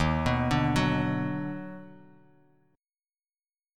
EbmM7#5 Chord
Listen to EbmM7#5 strummed